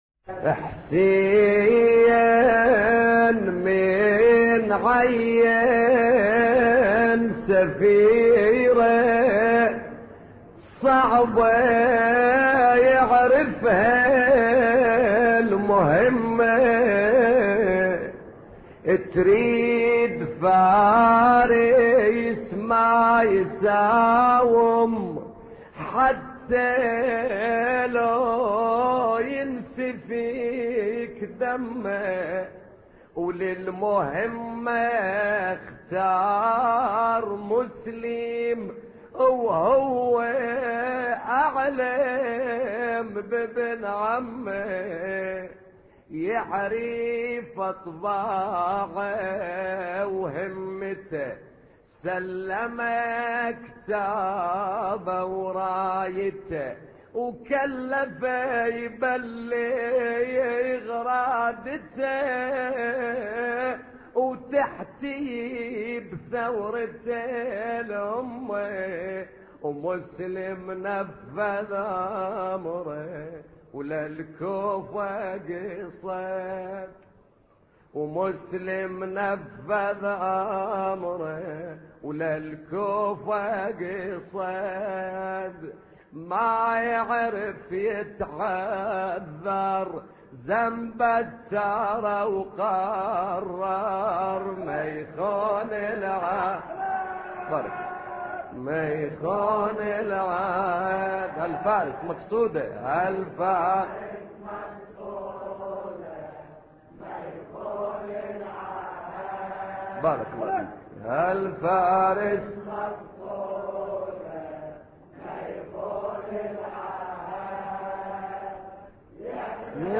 مراثي أهل البيت (ع)